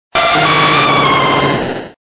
Cri de Lugia dans Pokémon Diamant et Perle.